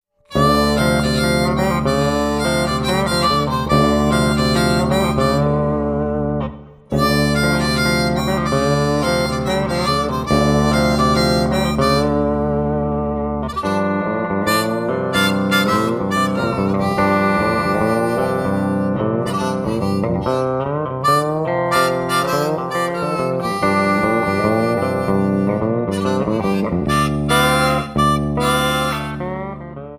Lap Slide Steel Guitar
Harmonica
A lap slide guitar and a harmonica.
an instrumental blues duet